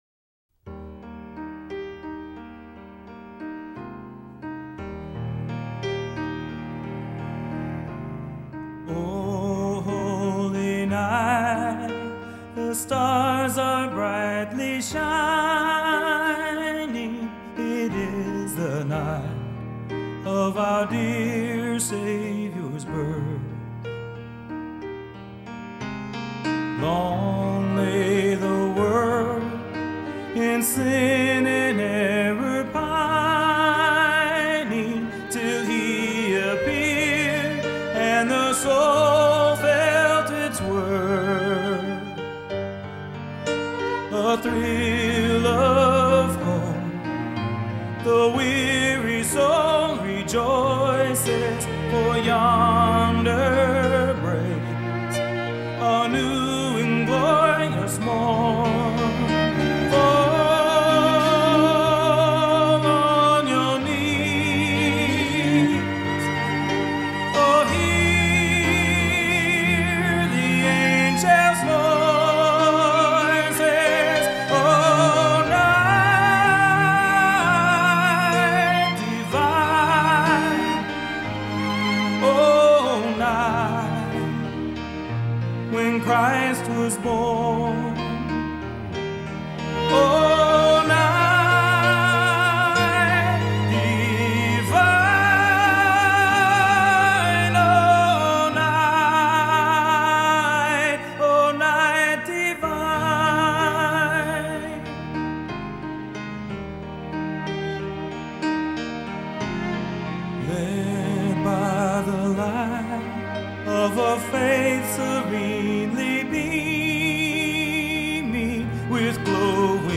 Inspirational Song – O Holy Night